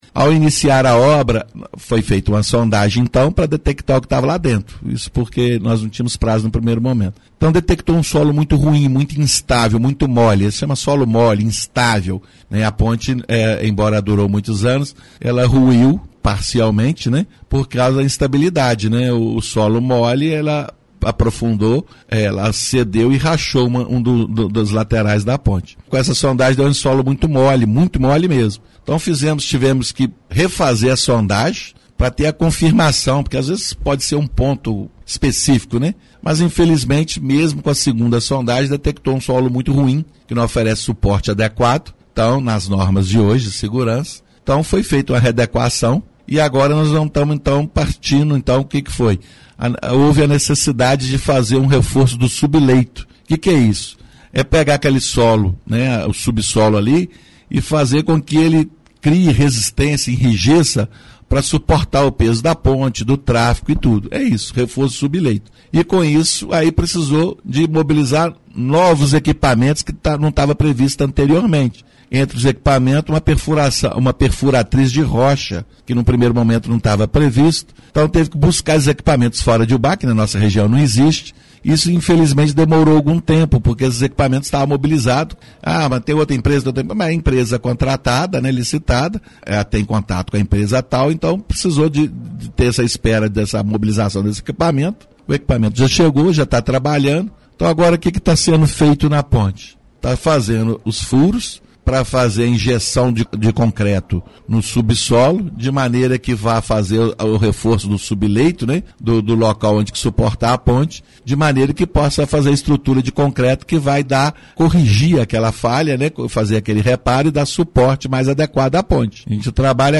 o secretário municipal de Obras João Gomes detalhou os motivos do atraso da entrega da ponte e definiu que possivelmente em Outubro seja liberado a Ponte.